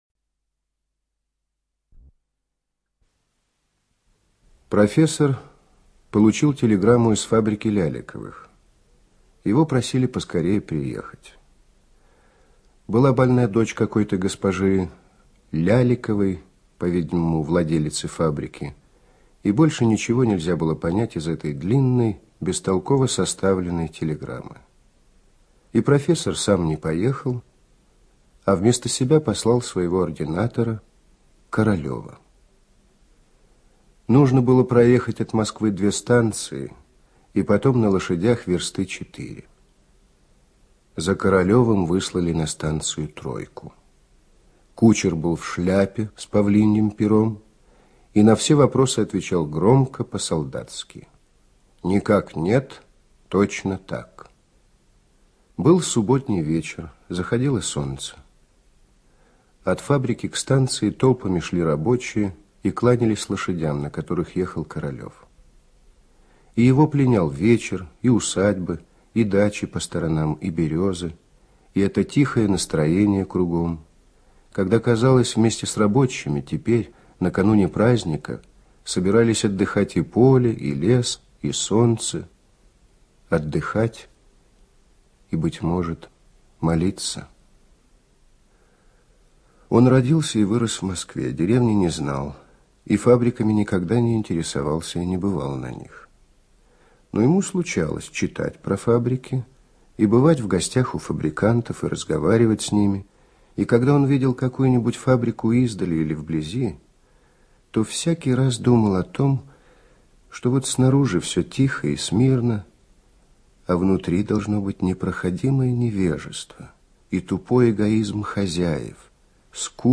ЧитаетГубенко Н.